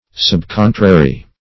Subcontrary \Sub*con"tra*ry\, a.